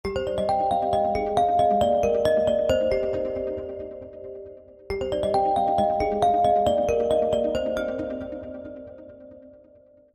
It’s restrained and familiar